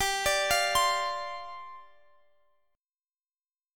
Listen to G7sus4 strummed